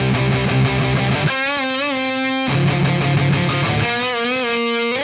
AM_RawkGuitar_95-C.wav